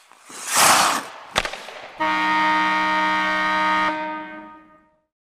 Звуки хоккея
Звук удара шайбы и гол с сиреной